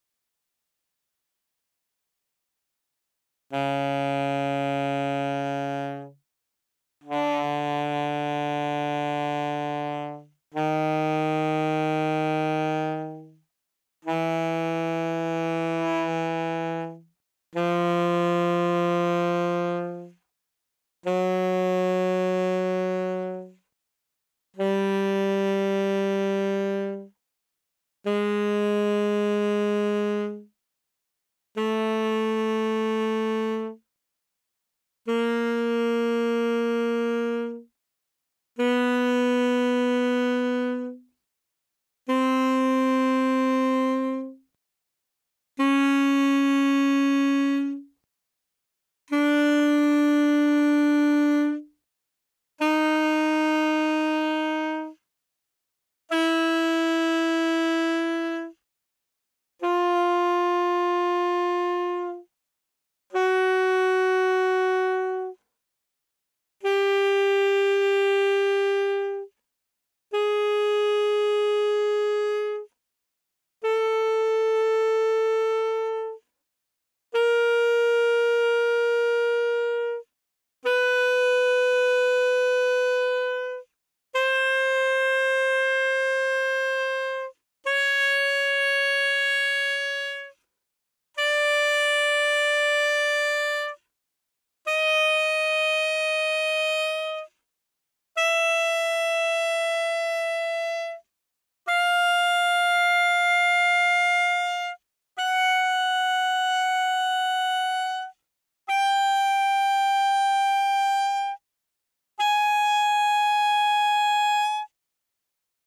altosax_novib_uiowa_33.wav